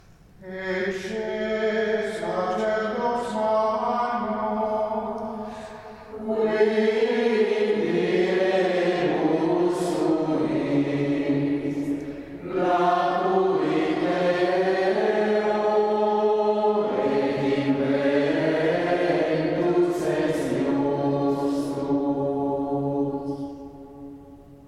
Antifone Ambrosiane Itinerario Giubilare (Audio)